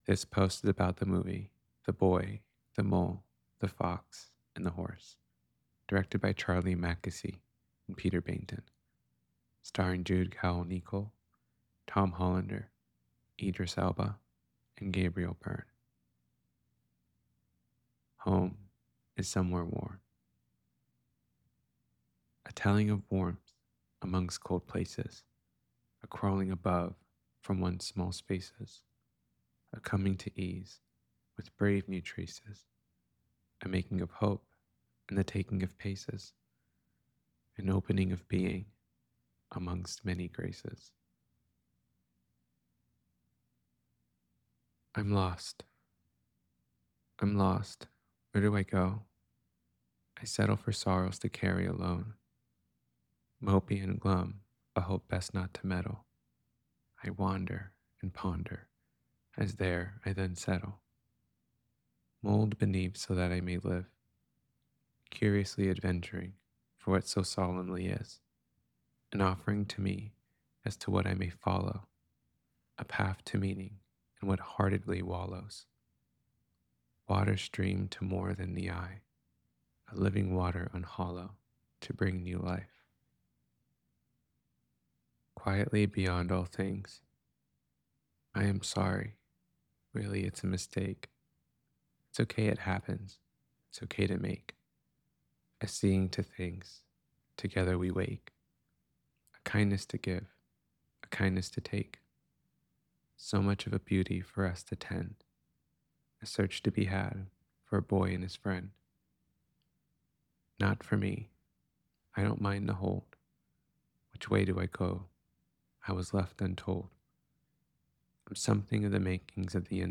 the-boy-the-mole-the-fox-and-the-horse-to-know-a-story-reading.mp3